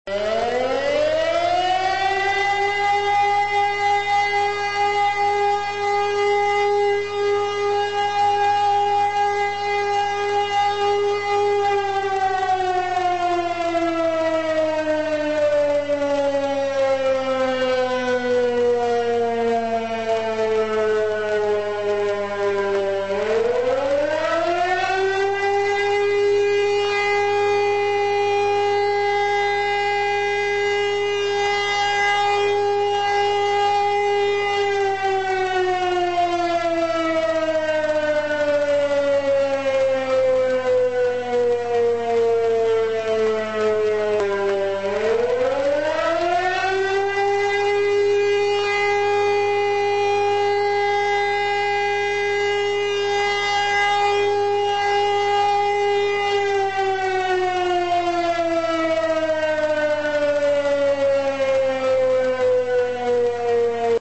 Alarmierung
sirene.mp3